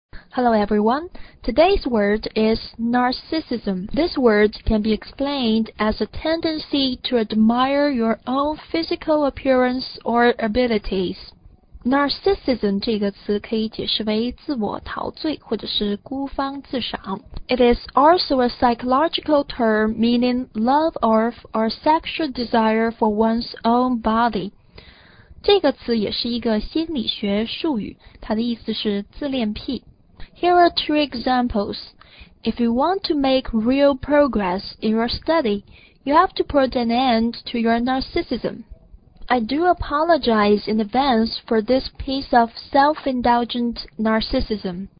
narcissism共4个音节，第一音节重读。